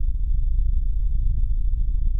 Ambience
Smuggler_Ship_Ambient_01.wav